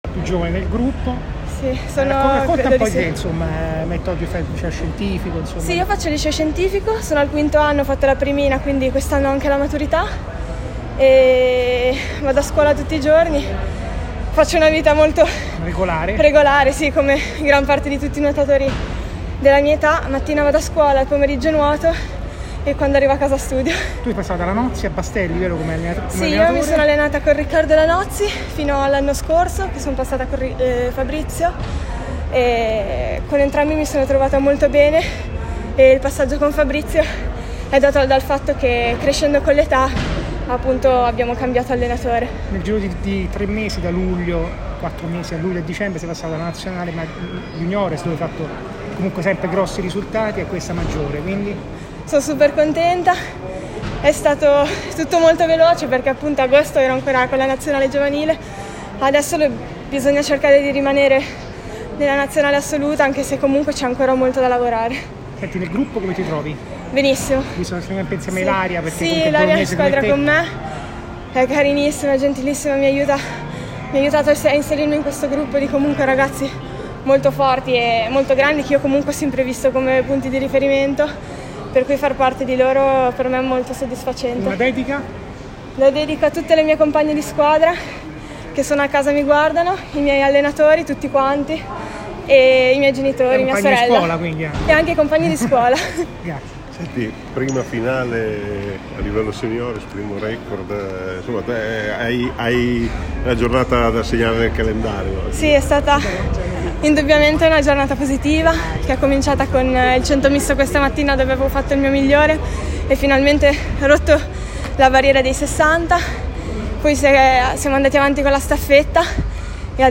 La veneta ha ottenuto la finale dei 100 stile libero, nuotando su un tempo vicino al proprio record italiano ed essendo particolarmente soddisfatta ai microfoni di OA Sport: “Sono contenta della mia prestazione e l’obiettivo era quello della finale.